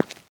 default_hard_footstep.2.ogg